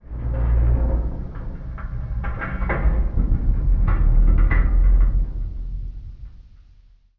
metal_low_creaking_ship_structure_04.wav